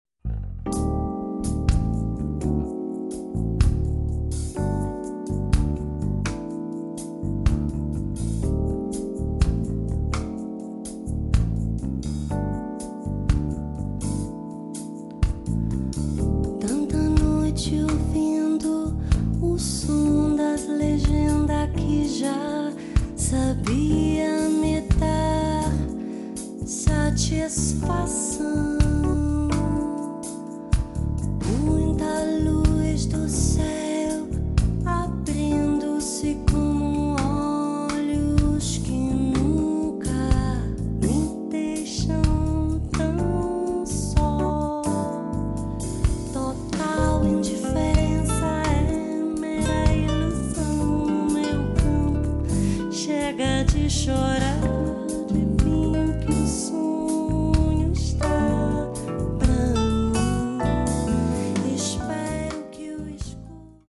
la musica brasiliana di qualità.